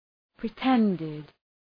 Προφορά
{prı’tendıd}